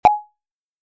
countdown.mp3